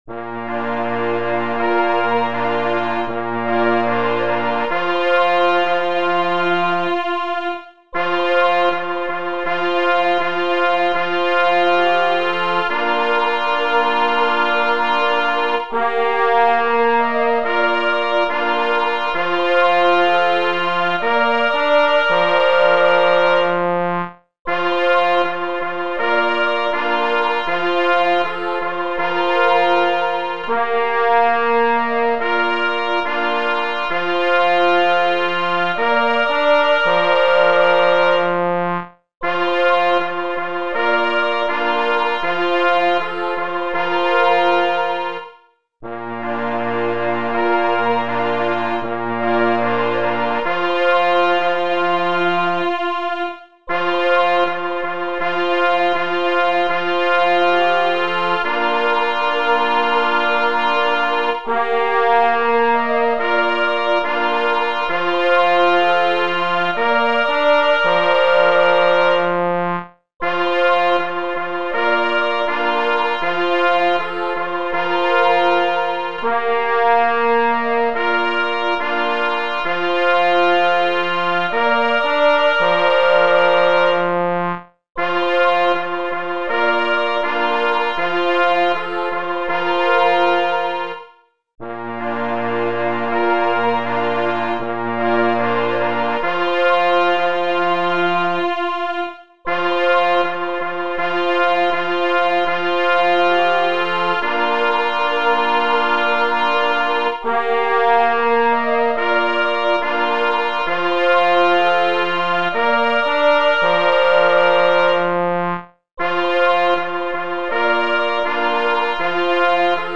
śpiew z towarzyszeniem plesa wentylowego, 2. plesów naturalnych
śpiew z tow. zespołu rogów myśliwskich